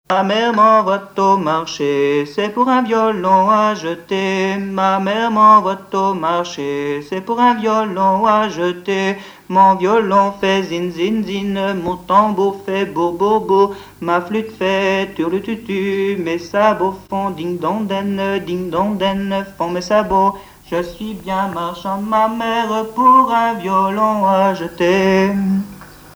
Genre énumérative
Pièce musicale inédite